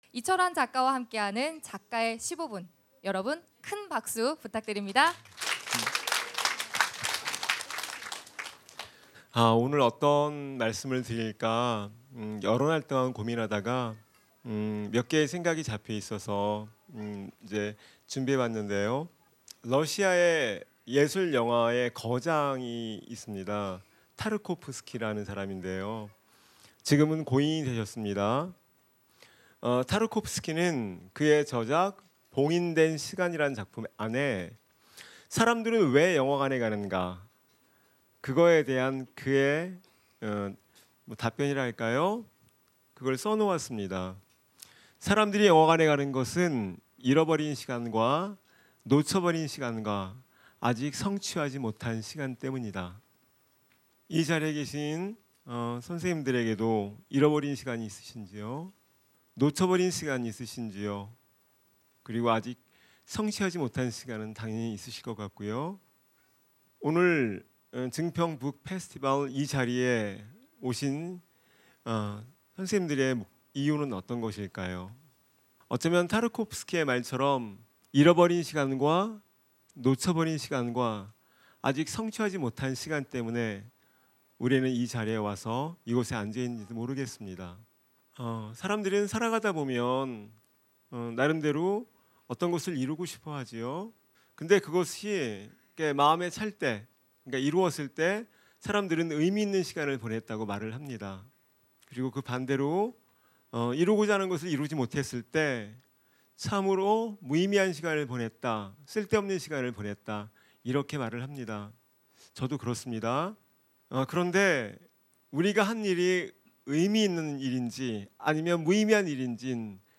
제2회 증평아고라북페스티벌에 초대된 이철환 작가와 나눈 대화 중에서 | 이번 북콘서트의 주제는 '위로'. 이철환 작가님에게 '작가의 15분' 특강을 부탁드렸습니다.
MBC충북 라디오로도 방송된 '작가의 15분' 음원을 함께 올려봅니다.
이철환작가_15분_제2회증평아고라북페스티벌.mp3